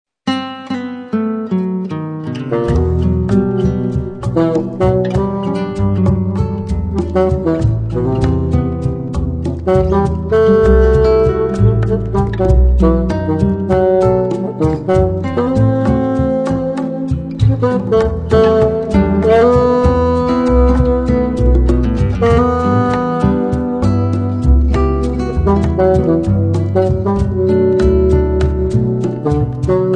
fagotto
clarinetto
chitarra
contrabbasso
percussioni